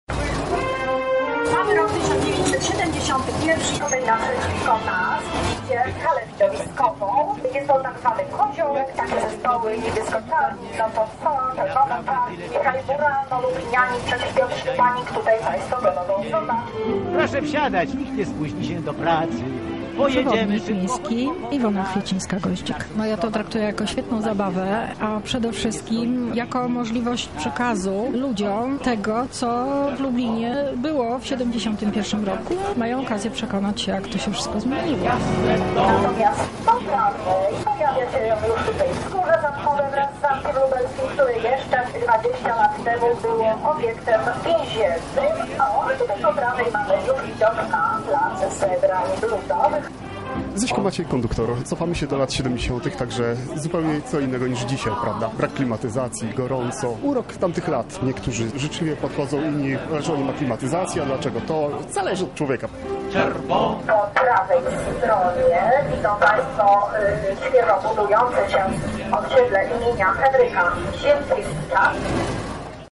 Śladami lat 70. na pokładzie Jelcza 272 Mexa wyruszyła nasza reporterka.